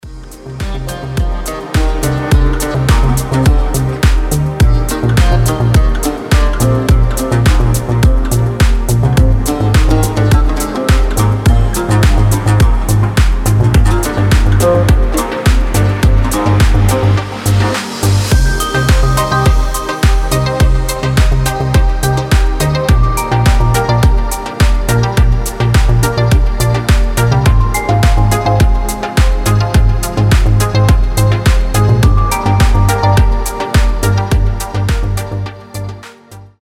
deep house
мелодичные
спокойные
без слов
Downtempo
восточные
расслабляющие
колокольчики
oriental house
Melodic house
Chill
Неимоверно красивая летняя музыка